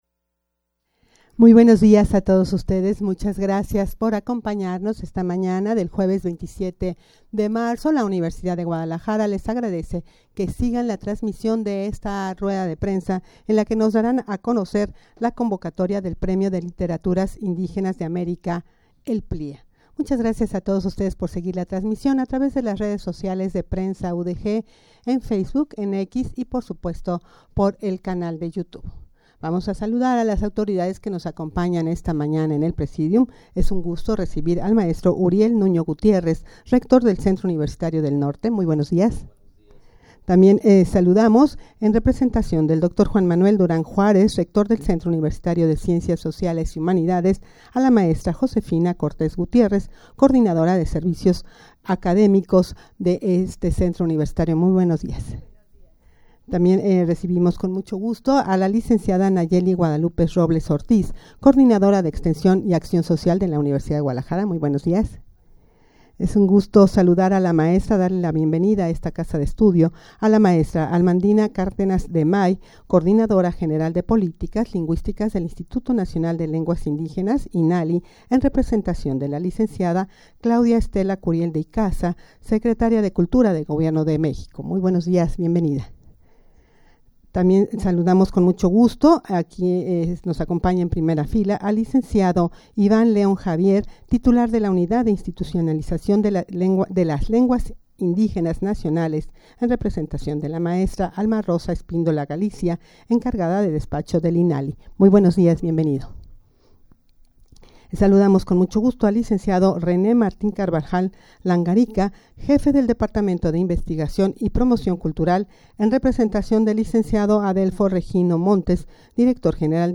Audio de la Rueda de Prensa
rueda-de-prensa-para-dar-a-conocer-la-convocatoria-del-premio-de-literaturas-indigenas-de-america-plia.mp3